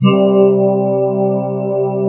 Index of /90_sSampleCDs/Best Service Dream Experience/SYN-PAD